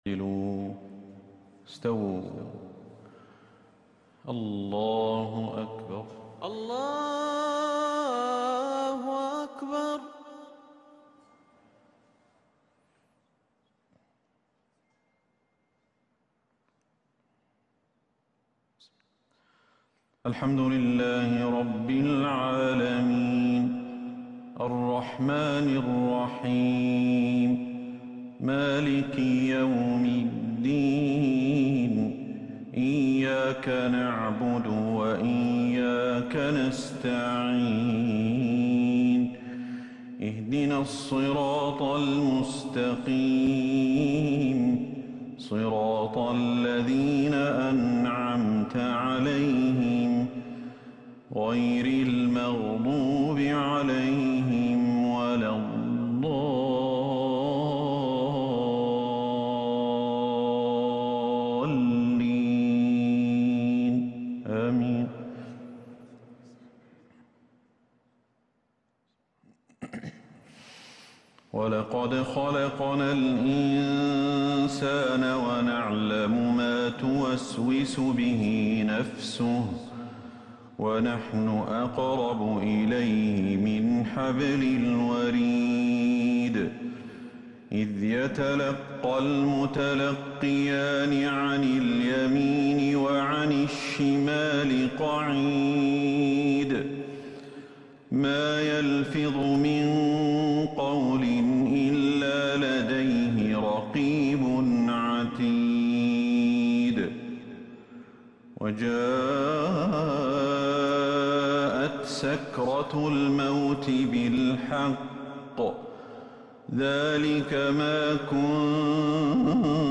عشاء الجمعة 5 محرم 1443 هـ ما تيسر من سورة {ق} > 1443 هـ > الفروض - تلاوات الشيخ أحمد الحذيفي